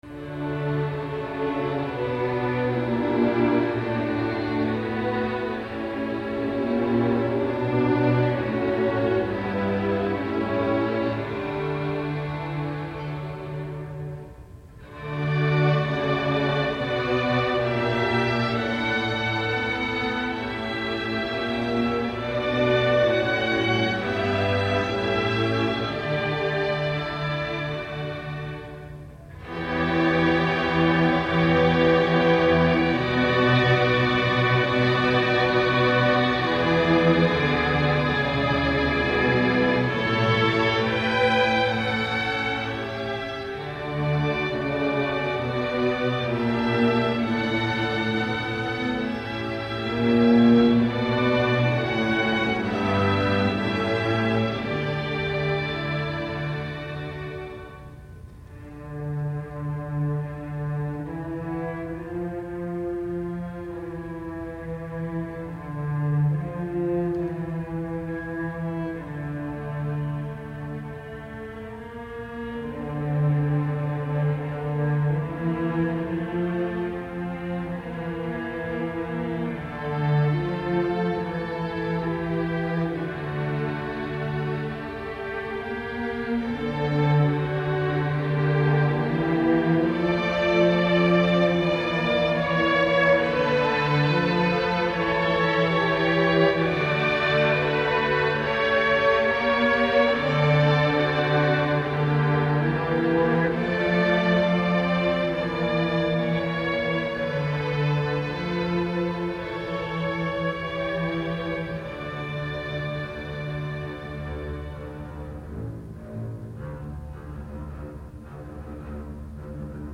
Rapsodie française
sous forme de choral, de canon et de fugue.